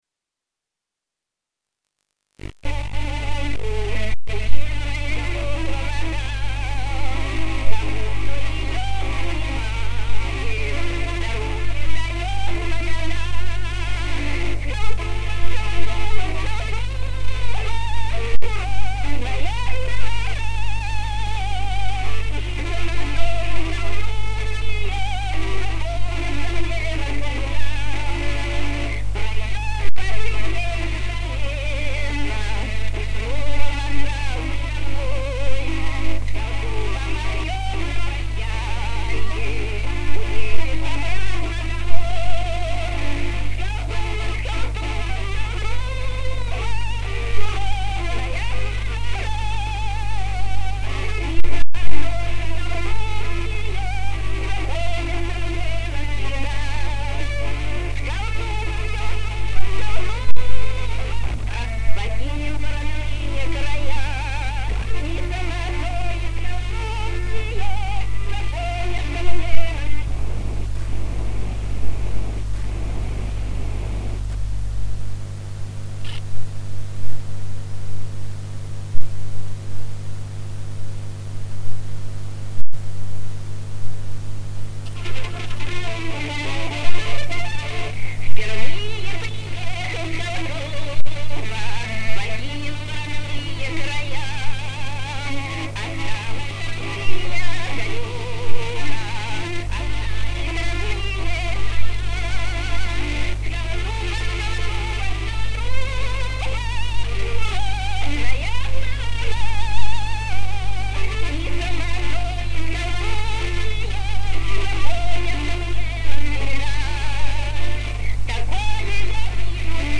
Запись плохая, песня старая,времен СССР.